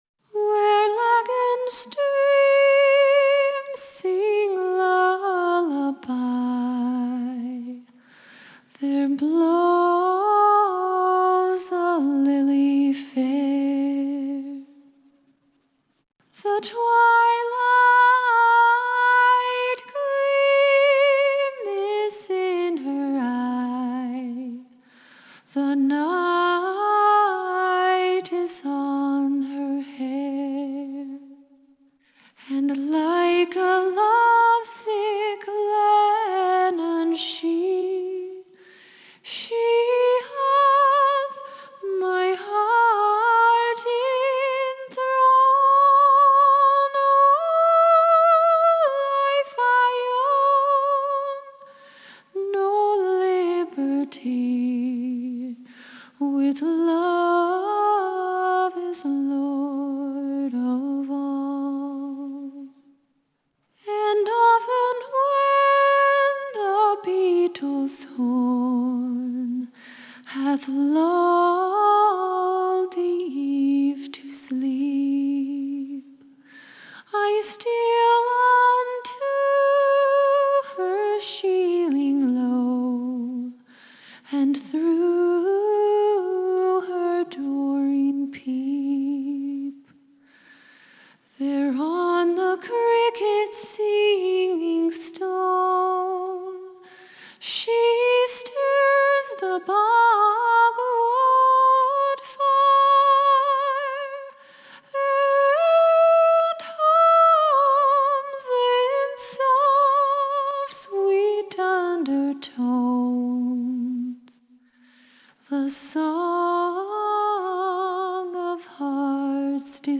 Genre: Electronic, Classical Crossover, Singer-SAongwriter